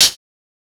Closet Hat